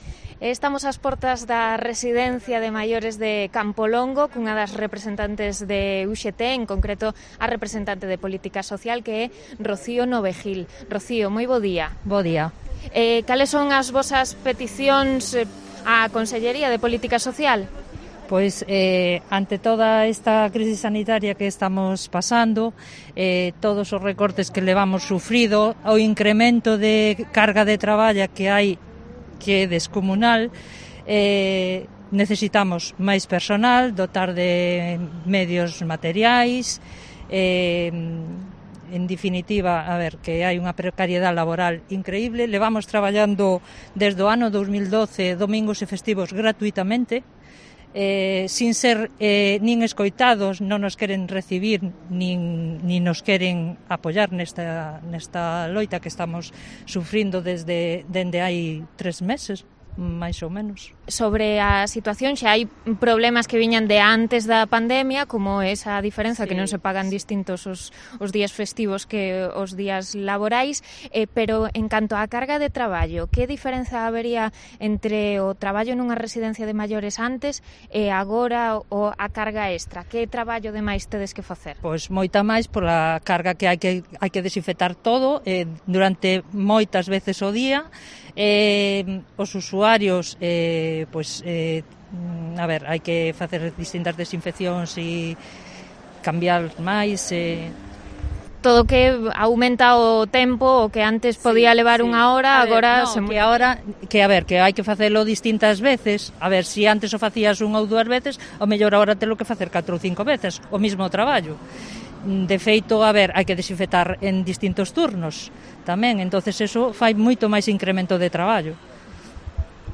Entrevista a una delegada sindical de la residencia de mayores de Campolongo